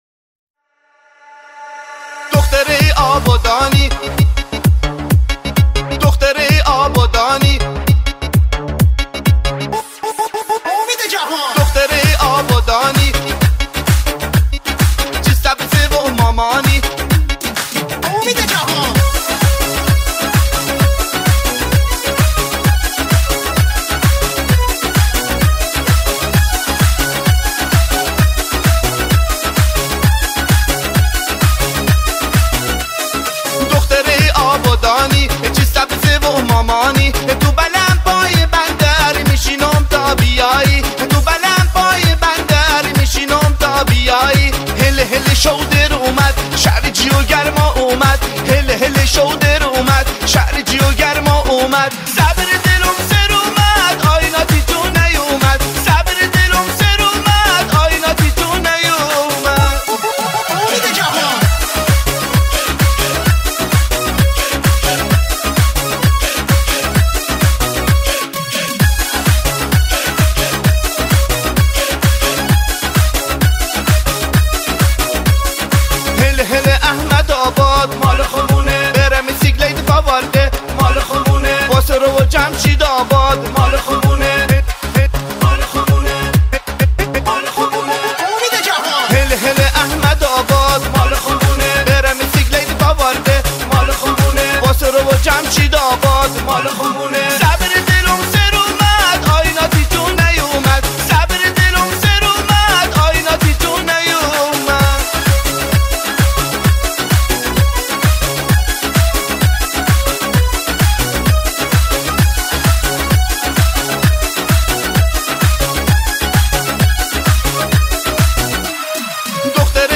بندری آبادانی